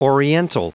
Prononciation du mot oriental en anglais (fichier audio)
Prononciation du mot : oriental